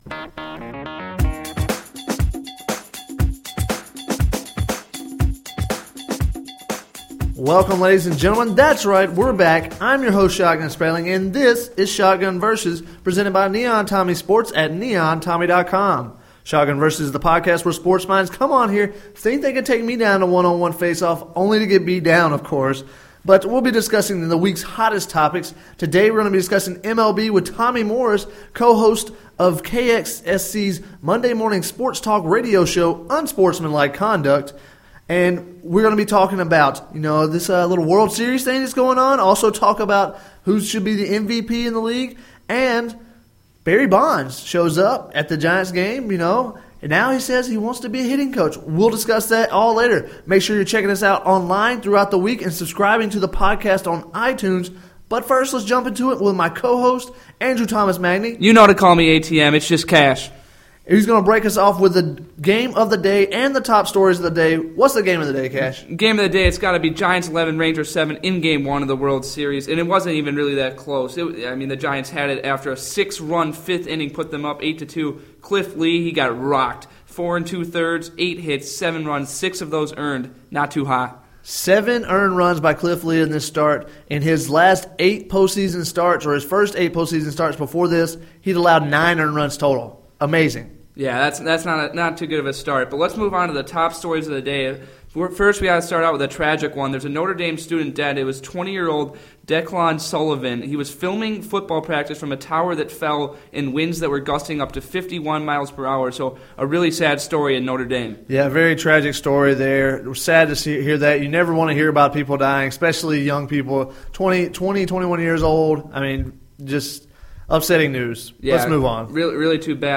PODCAST: Wednesday's top sports news and MLB debate.